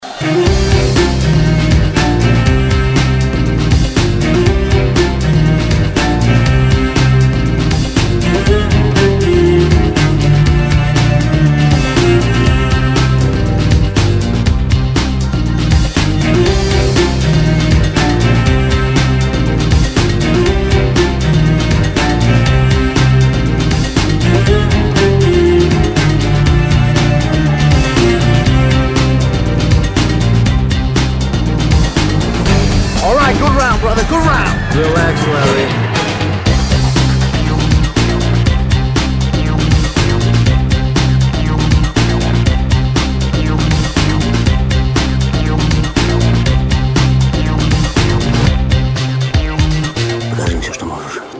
• Качество: 160, Stereo
без слов